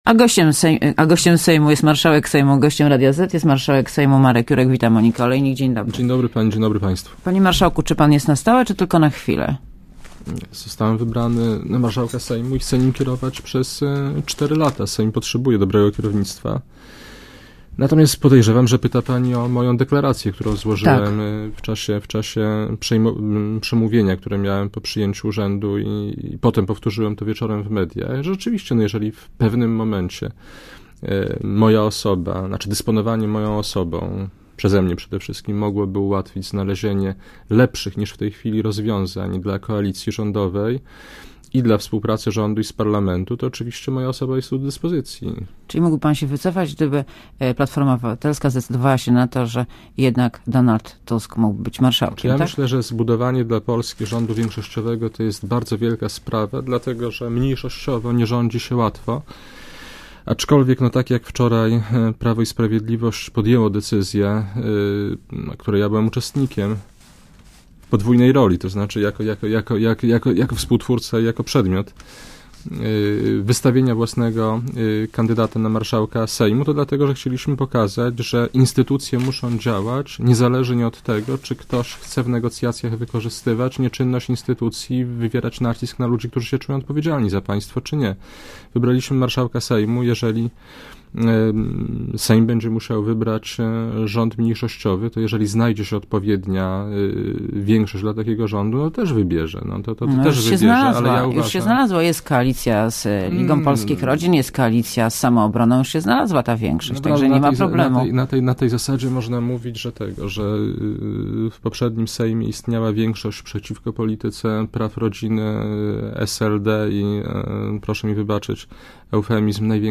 Posłuchaj wywiadu Gościem Radia ZET jest Marek Jurek , marszałek Sejmu, wita Monika Olejnik, dzień dobry.